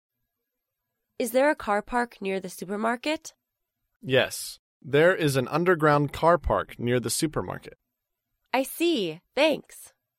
在线英语听力室高频英语口语对话 第500期:寻找停车场(1)的听力文件下载,《高频英语口语对话》栏目包含了日常生活中经常使用的英语情景对话，是学习英语口语，能够帮助英语爱好者在听英语对话的过程中，积累英语口语习语知识，提高英语听说水平，并通过栏目中的中英文字幕和音频MP3文件，提高英语语感。